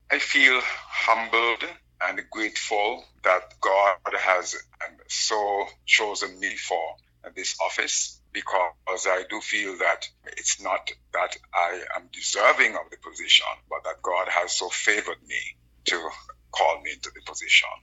Meantime, Dean Flemming briefly expressed how he felt.